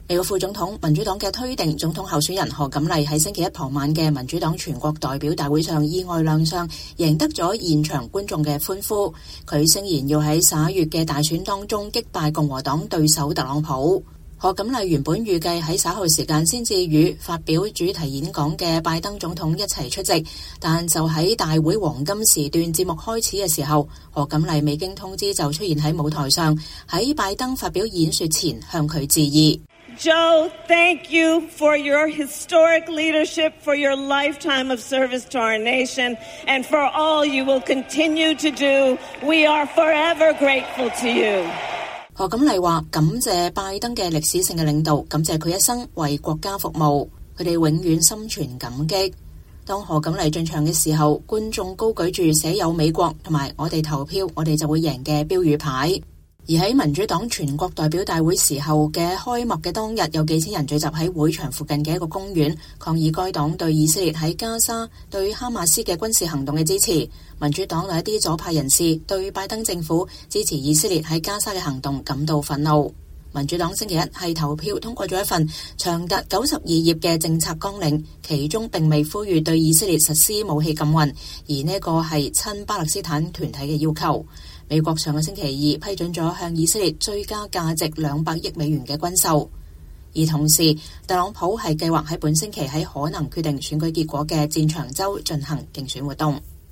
美國副總統、民主黨總統候選人賀錦麗 (又譯卡瑪拉·哈里斯 Kamala Harris) 在星期一(8月19日)傍晚的民主黨全國代表大會上意外亮相，贏得了現場觀眾的歡呼。她誓言要在11月的大選中擊敗共和黨對手唐納德·特朗普(Donald Trump)。